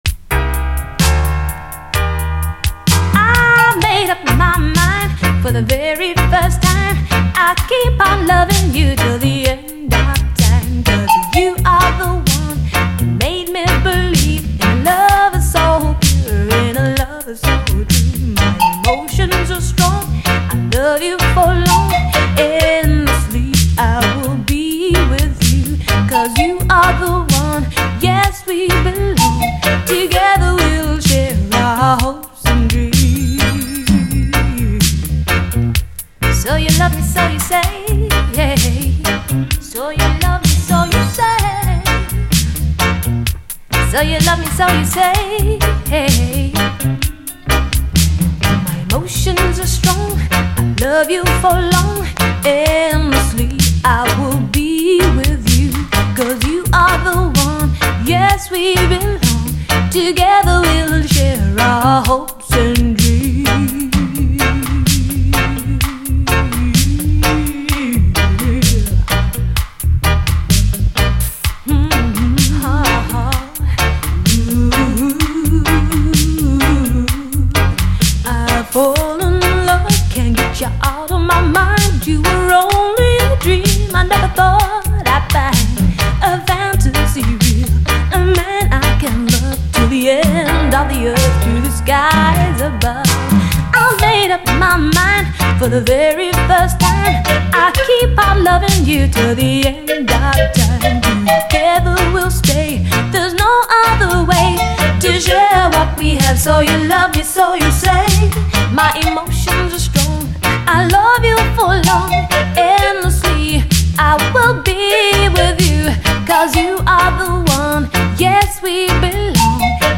優しく美しくカッコいいUKラヴァーズ・クラシック！
優しく美しく、同時にキリッとカッコいい。